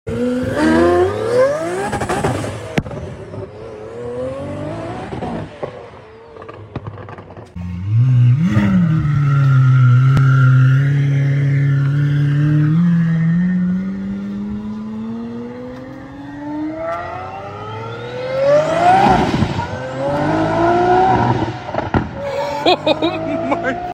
It has a Twin turbo and a Gintani exhaust.